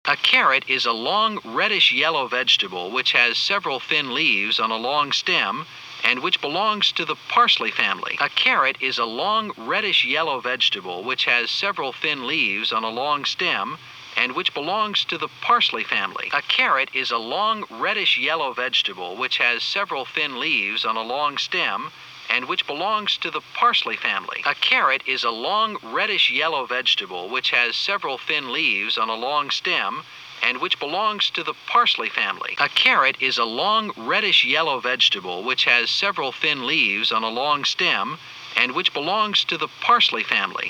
All files have the same presentation format: Peak input limiting level of 115 dB SPL, 105 dB SPL, 96 dB SPL, 92 dB SPL, and 115 dB SPL again. The first audio file is for speech at 60 dB SPL input.
Note that there is no real effect of peak input limiting level setting on the speech clarity since speech is a relatively quiet signal.